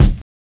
VINYL 2.wav